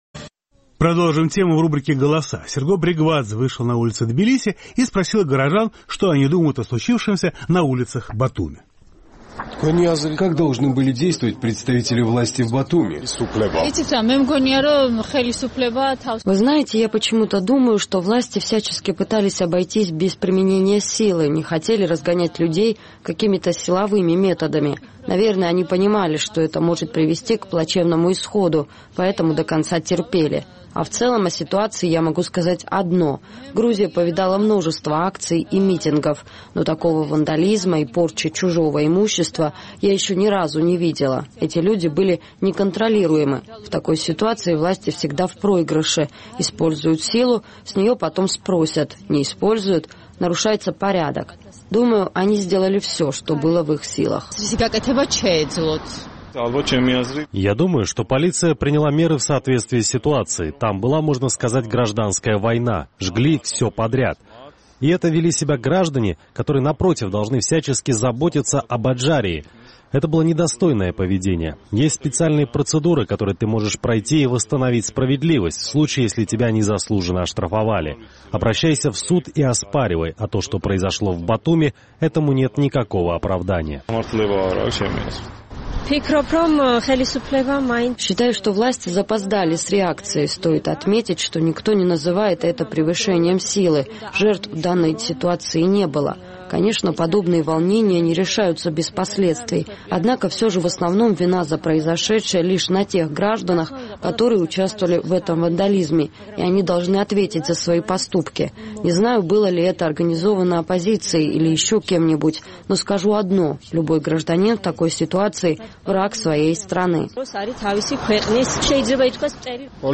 Беспорядки в Батуми закончились, а в обществе продолжают обсуждать произошедшее. Наш тбилисский корреспондент решил поинтересоваться у граждан, что они думают о произошедшем.